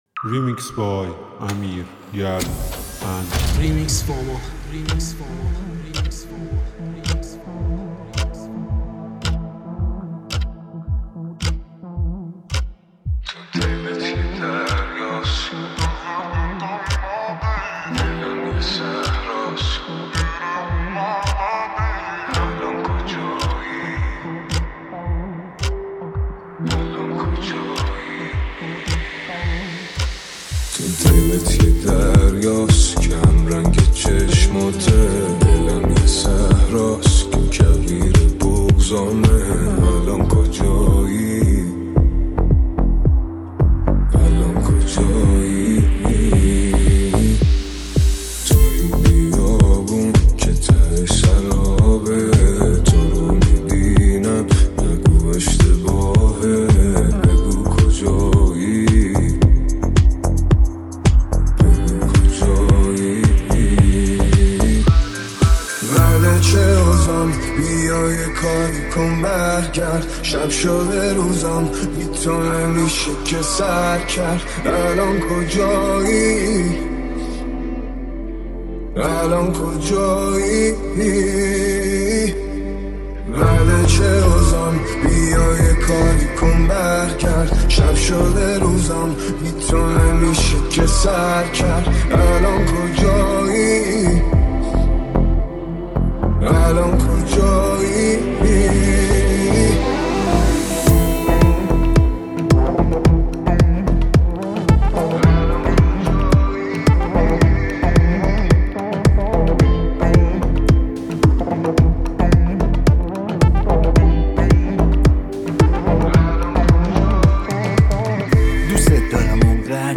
ریمیکس رپ اینستا غمگین